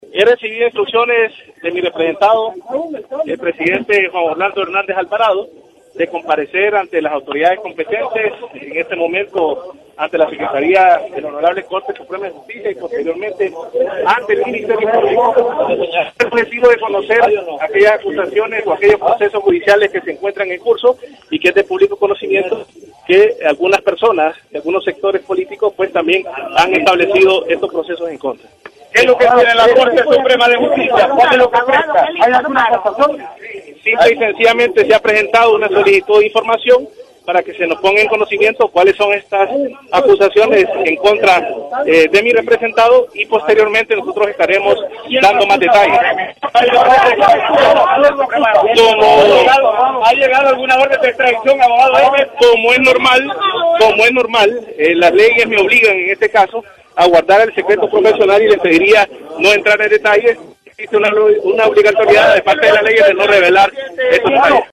Luego de su visita a la Corte Suprema de Justicia, el apoderado legal brindó una breve conferencia de prensa, donde explicó el motivo de su llegada al Poder Judicial.